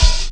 Open_Hat_(Cymbal_Steppa).wav